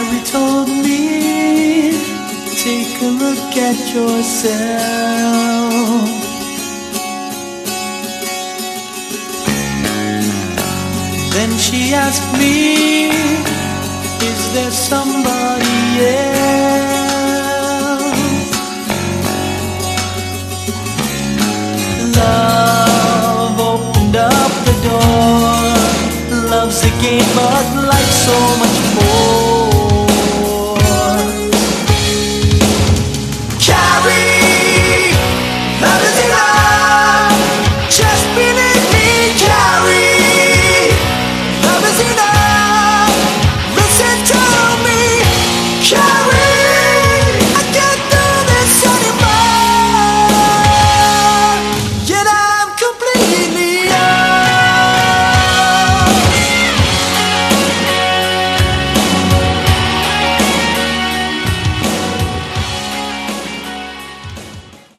Category: AOR/Hard Rock
drums, keyboards
guitars, basses